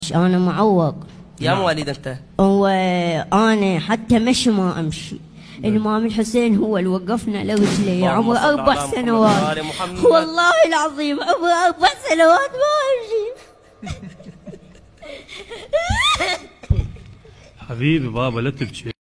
المذيع قاعد يضحك اصلا ههههههههههههه ههههههههههههههه ههههههههههههههه